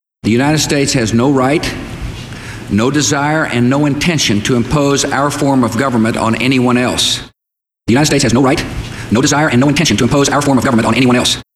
With Audacity you can increase the speed of speech without changing the pitch [ No Chipmunk] by using either “Change Tempo” , or “Sliding Time Scale”, ( IMO the latter is less computery ).